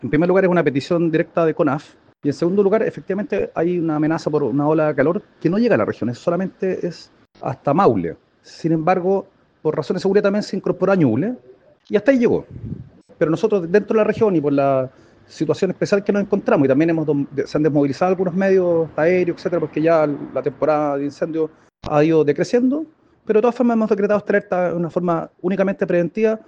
Según explicó el director de Senapred en la región, Alejandro Pliscoff, se trata de una ola de calor que llegará hasta Ñuble y que la medida en la zona solo responde a una acción de prevención.